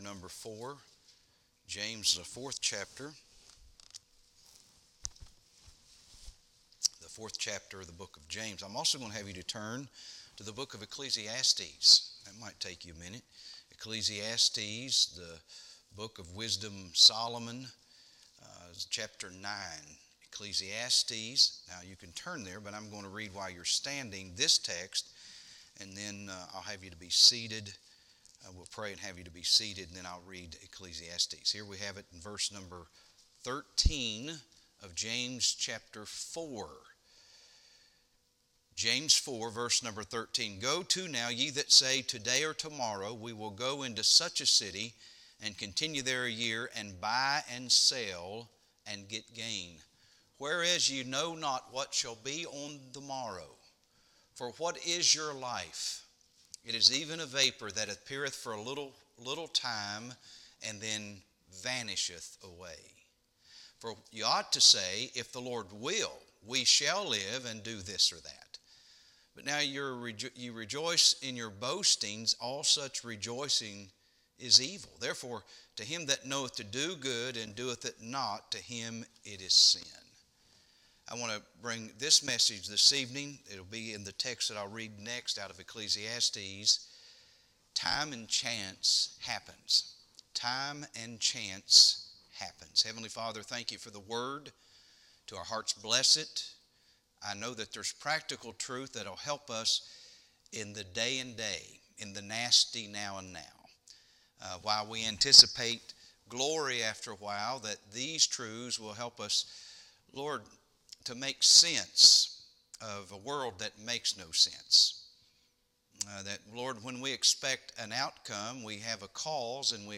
Sermons - Biblicist Ministries